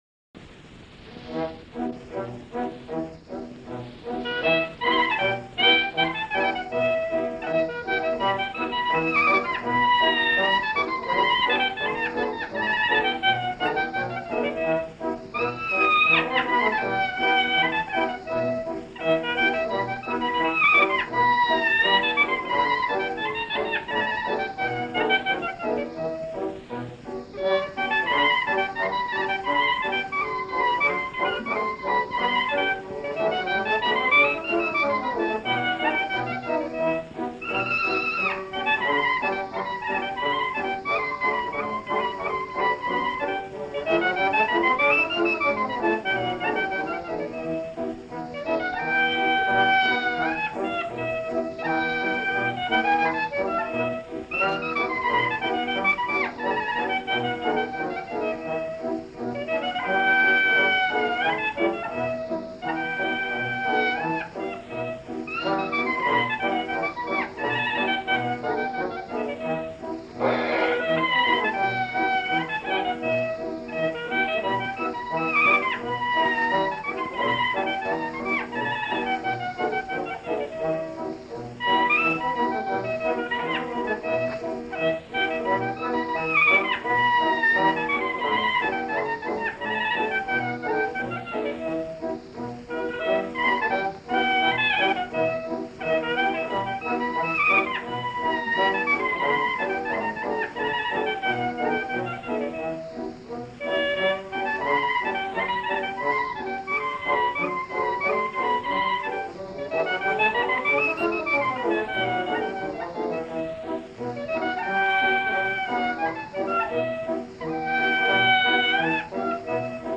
LE PROTO-KLEZMER